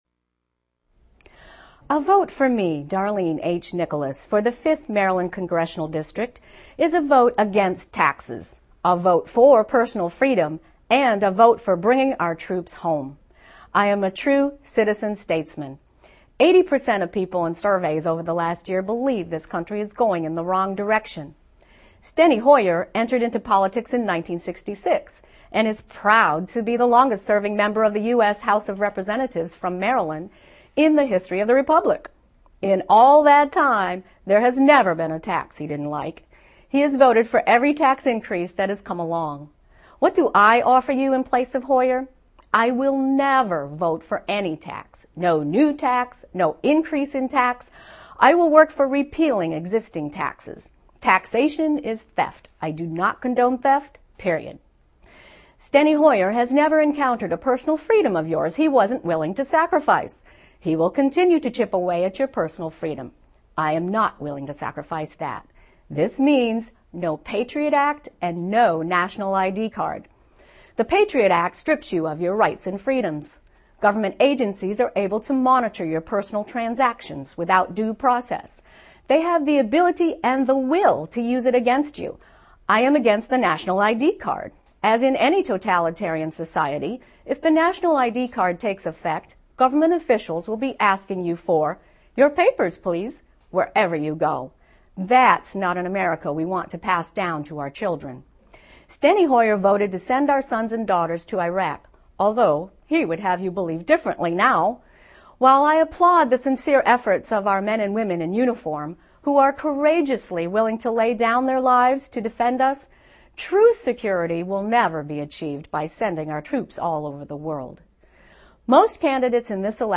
Cable TV Spot